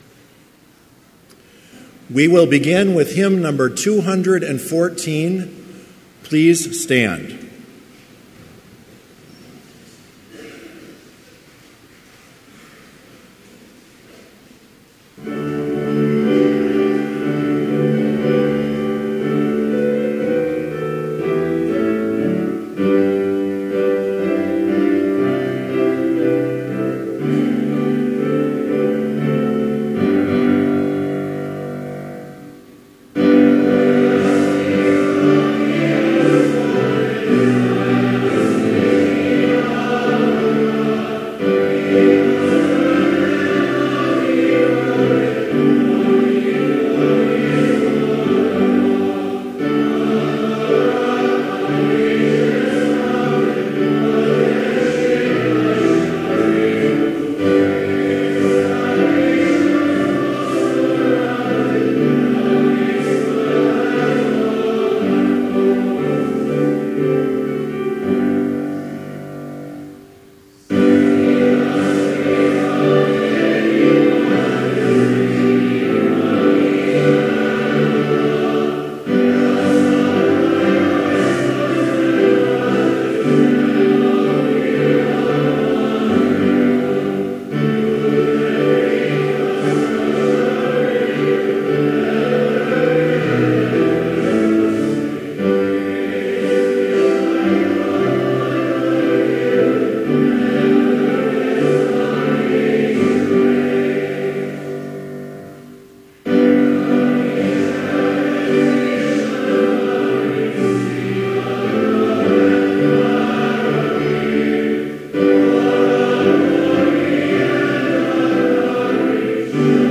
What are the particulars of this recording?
Complete service audio for Chapel - March 28, 2017